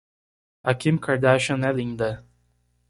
Pronounced as (IPA) /ˈkĩ/